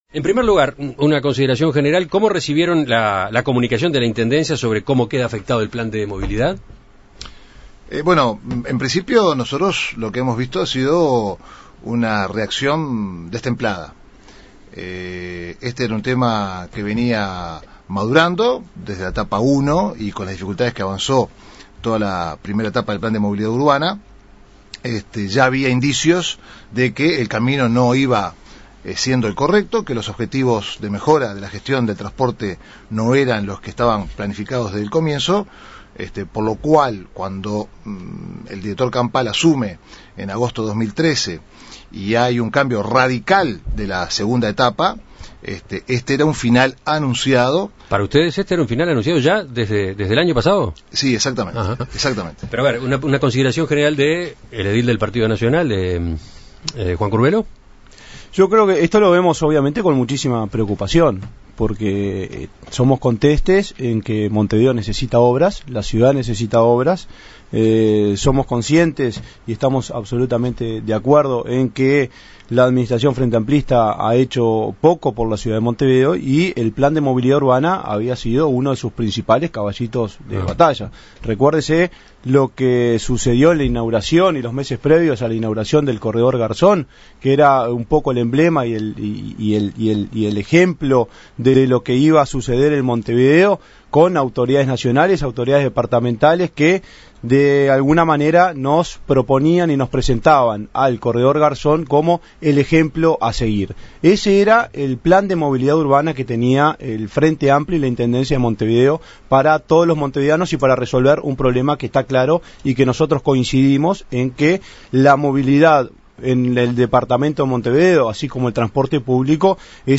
En esta oportunidad, la entrevista fue con los ediles Mario Barbato del Partido Colorado y Juan Curbelo del Partido Nacional.